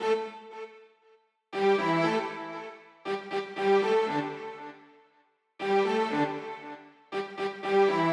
28 Strings PT1.wav